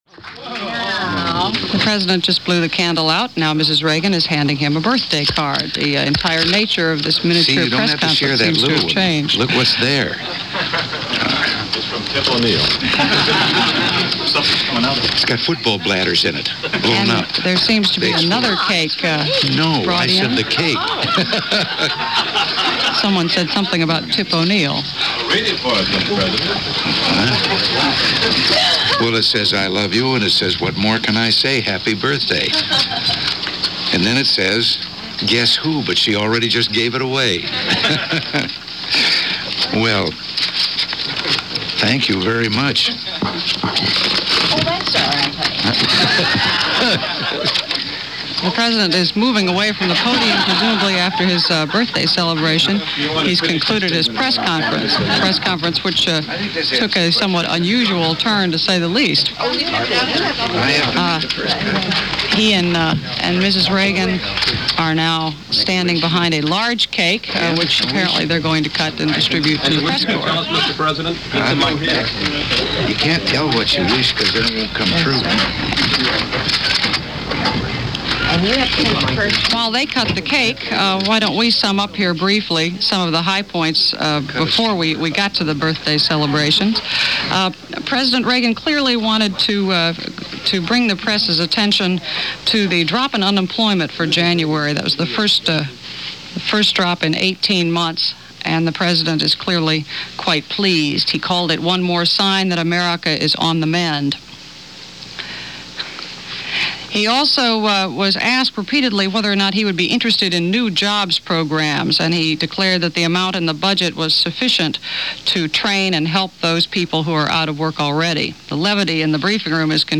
This February 4th in 1983 started off with a surprise birthday party in the midst of a Presidential Press conference.
And so went another day of contrasts on Planet Earth, this February 6th in 1983, as presented by CBS Radio and the 9:00 am news with Richard C. Hottelet.